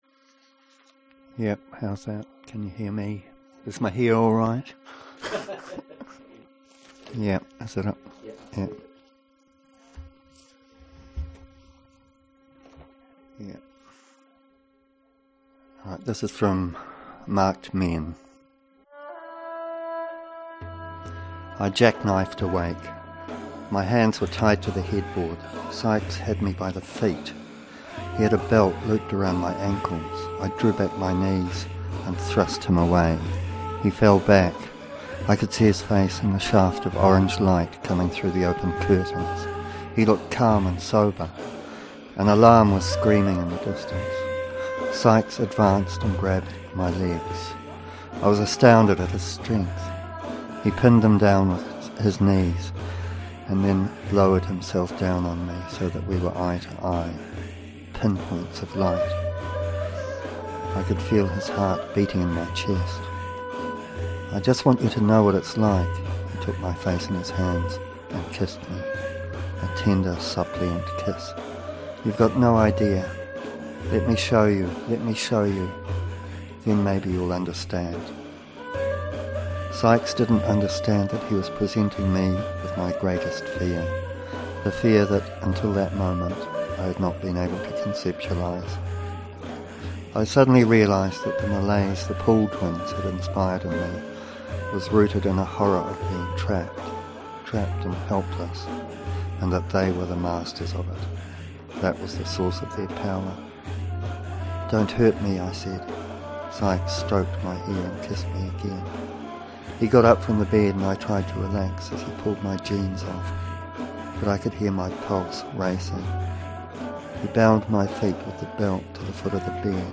reading from Marked Men with backing music.